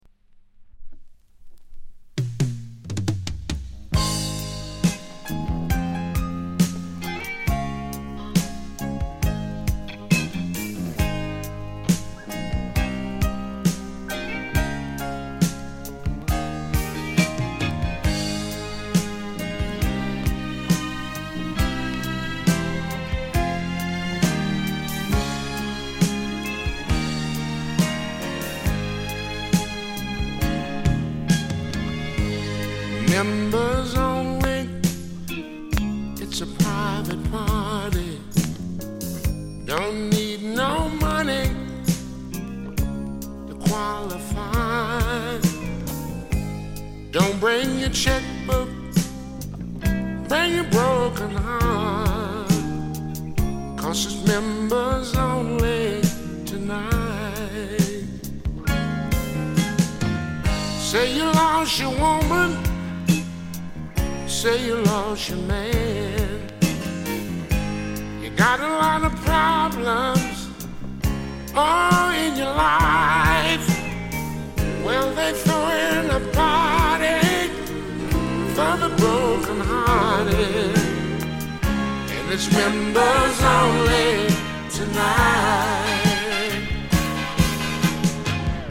概ね美盤ですが、A面 40秒辺りにキズ。パチつく箇所あり。飛びません。